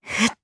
Laudia-Vox_Casting1_jp.wav